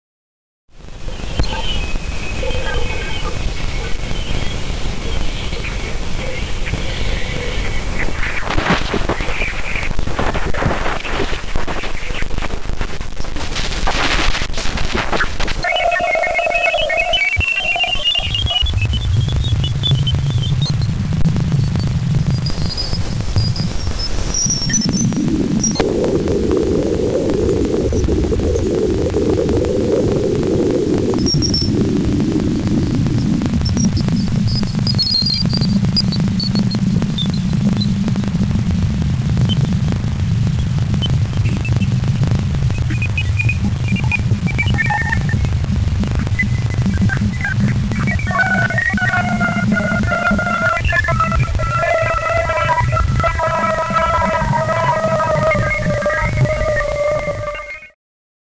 Звуки Луны
Лунные помехи